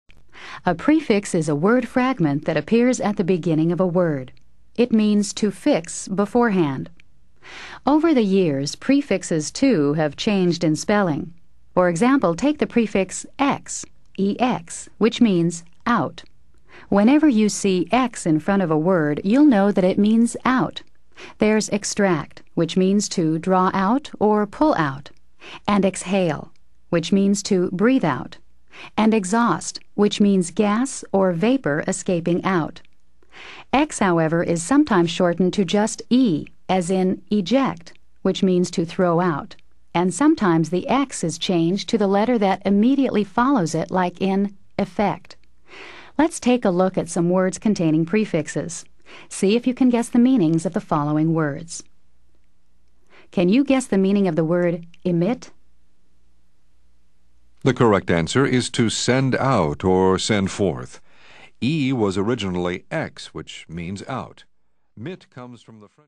Learn 1000s of new words, each pronounced, spelled, defined, and used in a sentence.